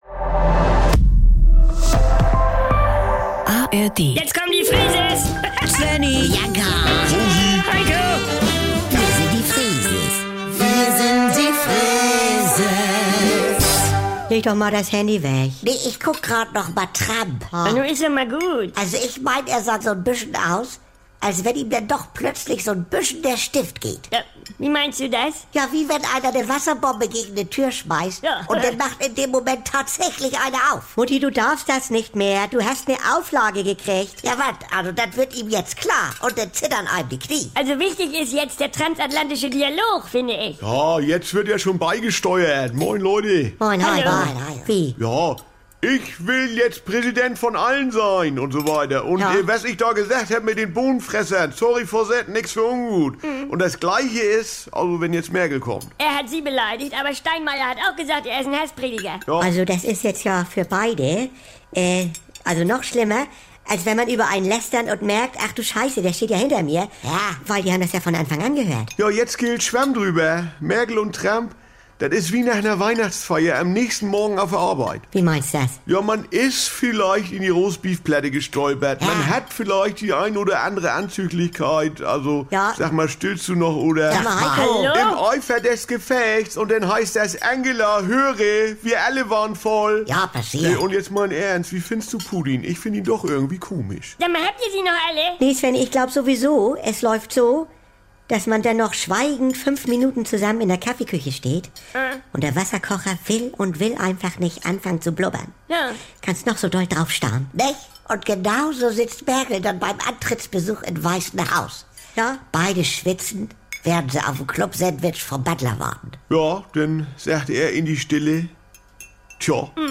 Jederzeit und so oft ihr wollt: Die NDR 2 Kult-Comedy direkt aus